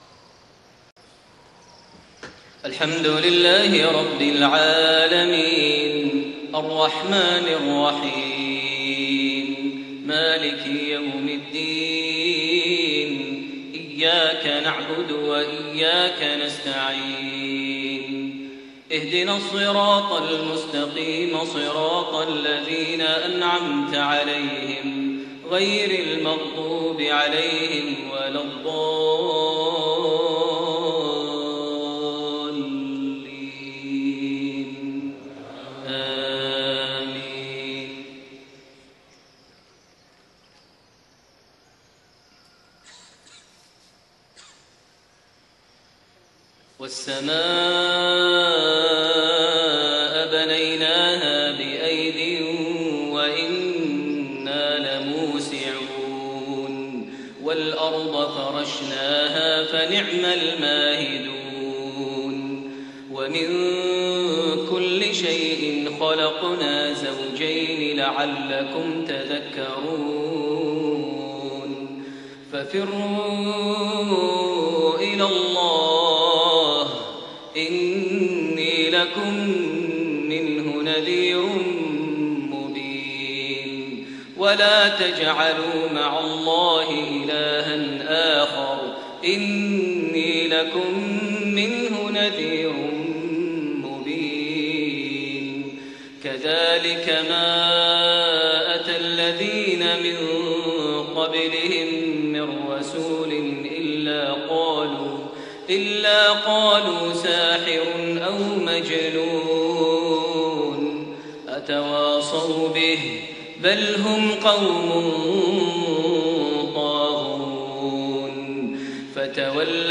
صلاة المغرب4-4-1432 من سورة الذاريات 47-60 > 1432 هـ > الفروض - تلاوات ماهر المعيقلي